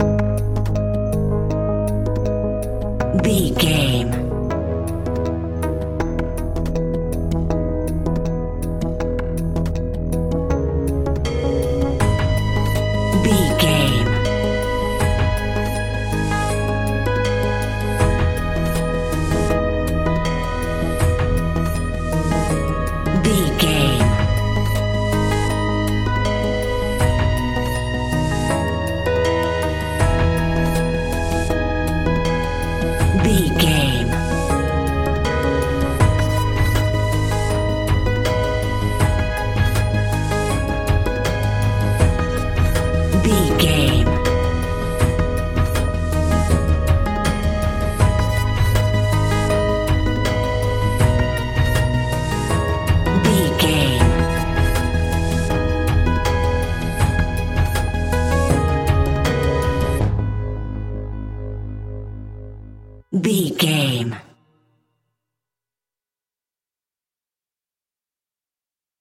Aeolian/Minor
G#
ominous
dark
eerie
electric piano
percussion
drums
synthesiser
strings
horror music